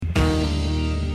Drums and cymbals crash.